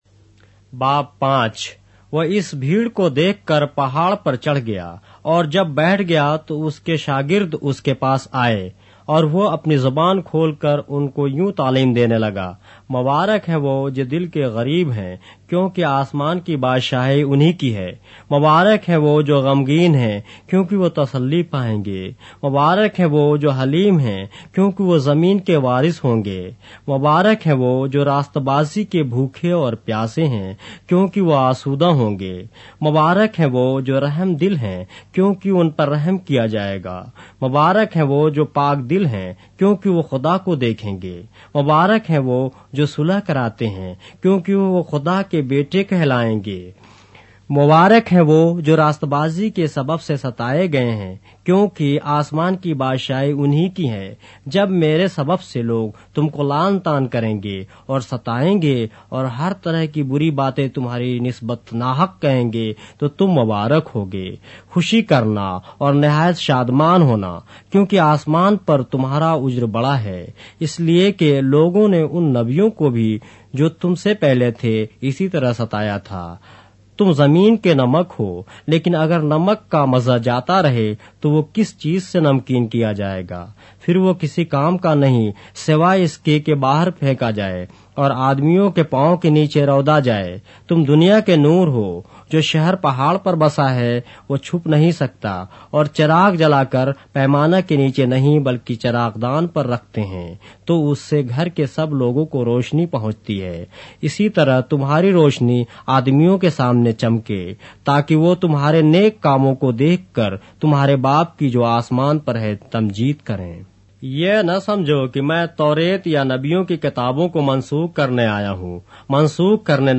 اردو بائبل کے باب - آڈیو روایت کے ساتھ - Matthew, chapter 5 of the Holy Bible in Urdu